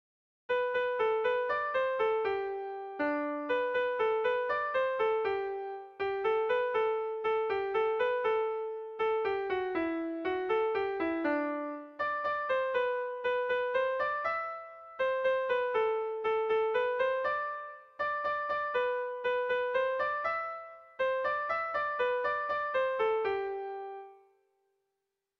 Erlijiozkoa
ABD1D2